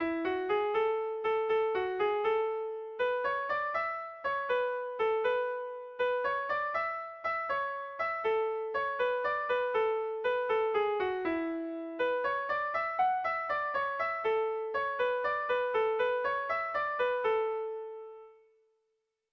Bertso melodies - View details   To know more about this section
Irrizkoa
Hazparne < Lapurdi Ekialdea < Lapurdi < Basque Country
ABBDBE